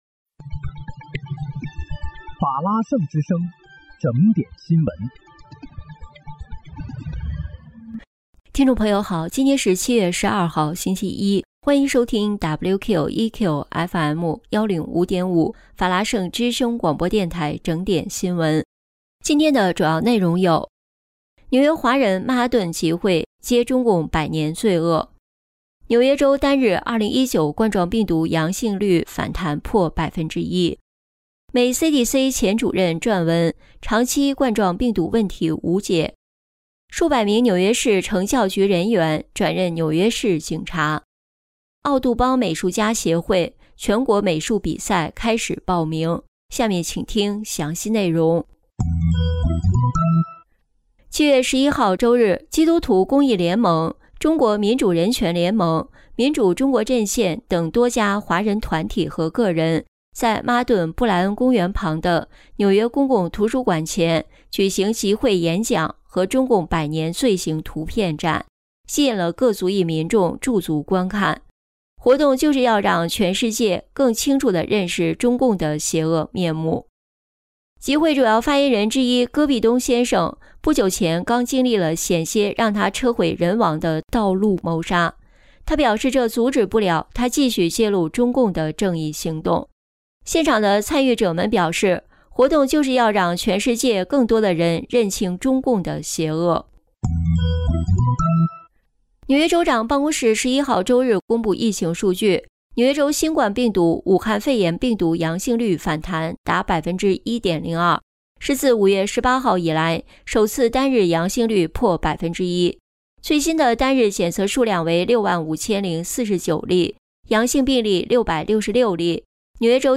7月12日（星期一）纽约整点新闻